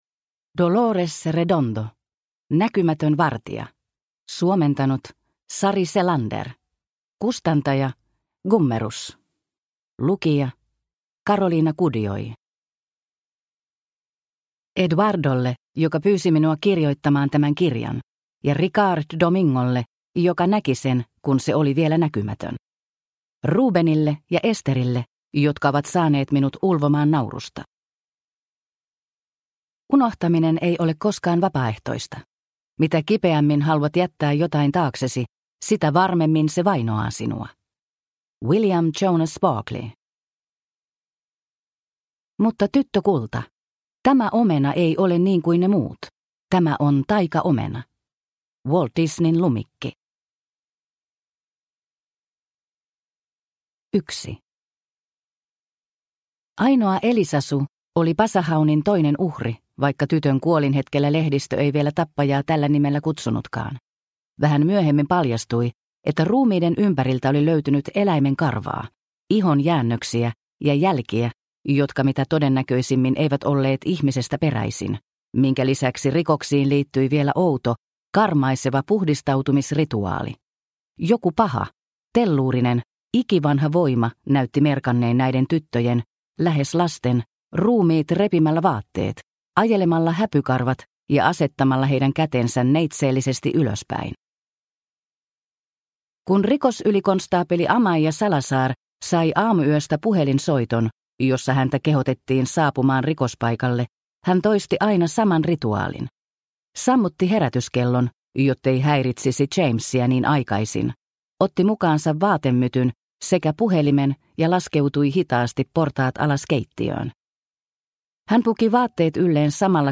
Näkymätön vartija – Ljudbok – Laddas ner